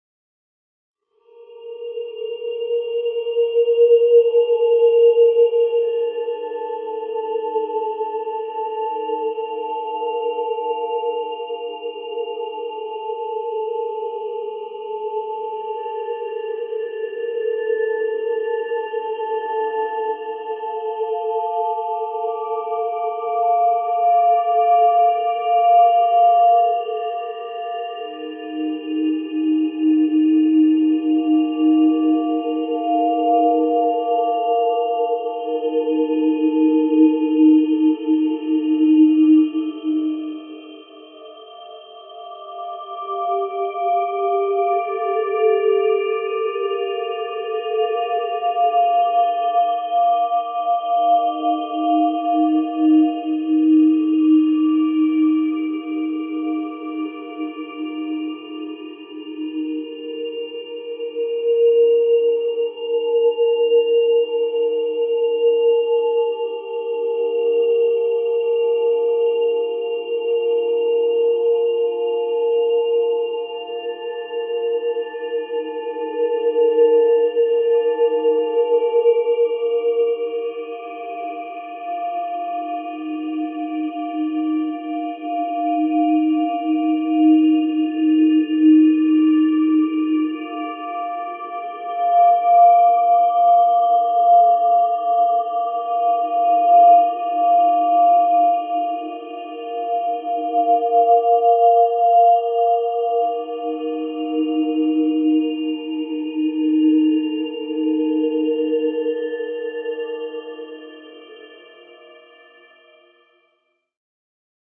I used vowels included to the text below at uta2 and uta4.
(1:56)  0.9MB  convolved